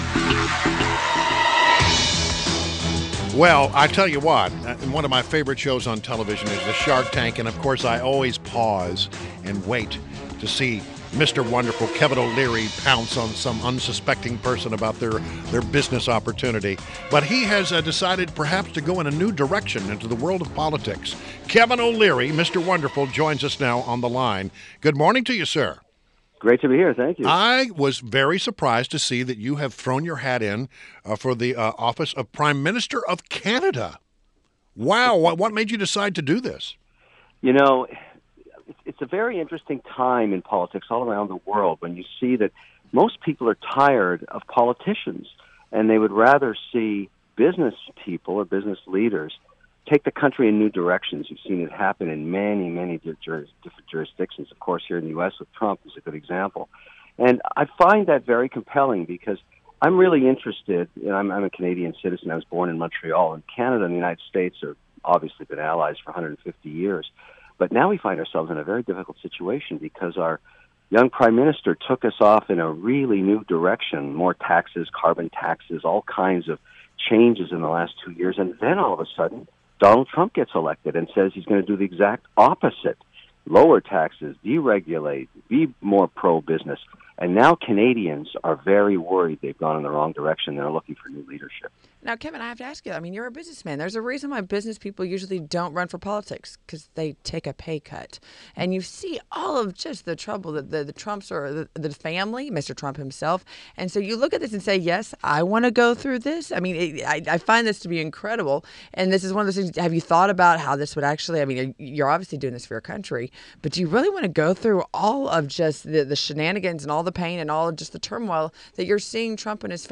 WMAL Interview - KEVIN O'LEARY - 01.19.17